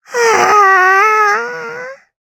Taily-Vox_Sigh_jp.wav